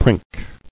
[prink]